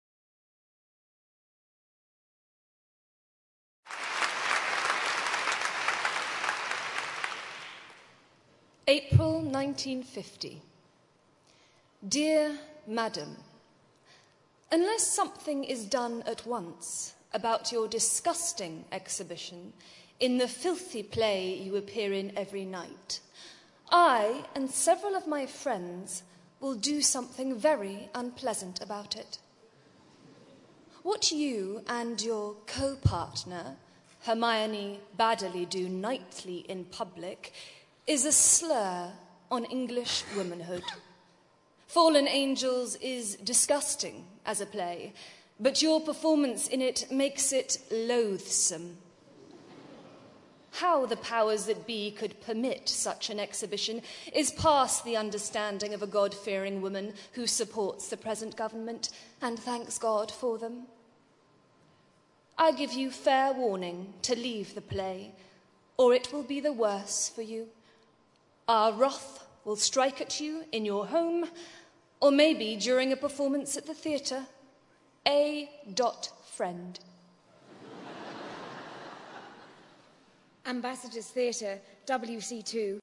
在线英语听力室见信如晤Letters Live 第22期:'露易丝·布瑞莉&索菲·亨特'读信:亲爱的朋友(1)的听力文件下载,《见信如唔 Letters Live》是英国一档书信朗读节目，旨在向向书信艺术致敬，邀请音乐、影视、文艺界的名人，如卷福、抖森等，现场朗读近一个世纪以来令人难忘的书信。